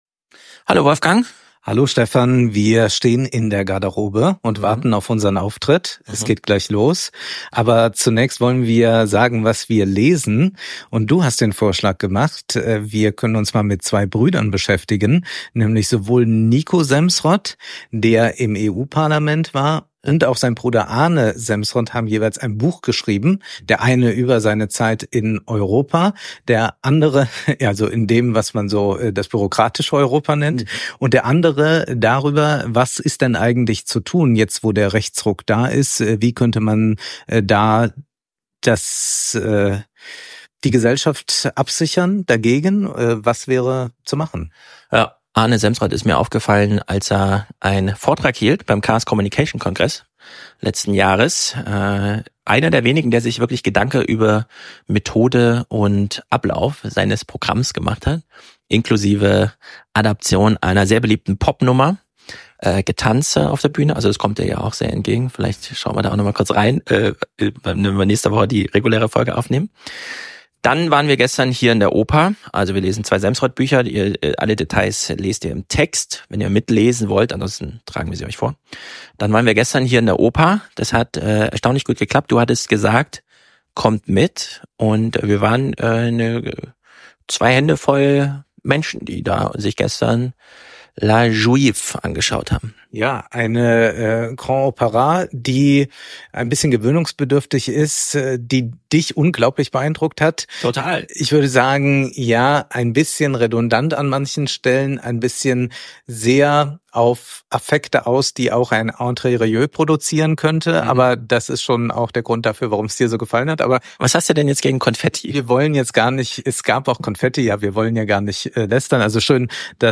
Live in Frankfurt: Kristallisieren, Passive Einkommen, Boomer-Talk, Emotionale KI
Wir begrüßen euch in Frankfurt.